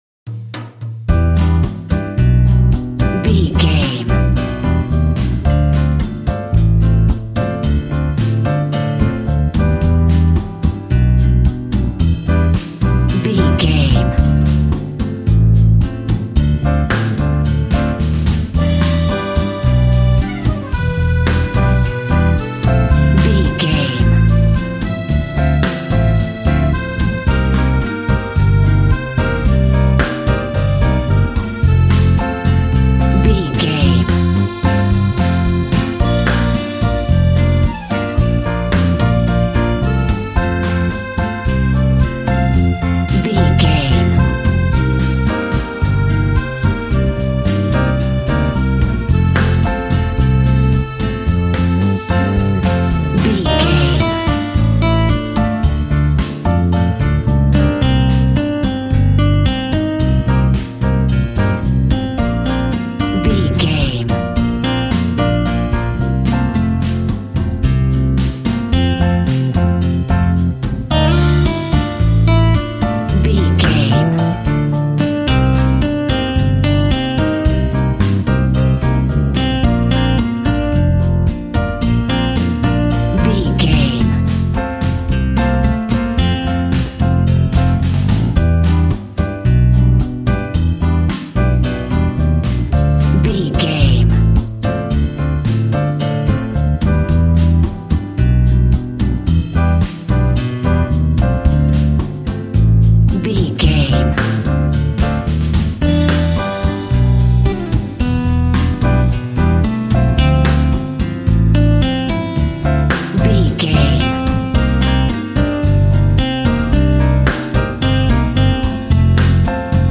Ionian/Major
funky
energetic
percussion
electric guitar
acoustic guitar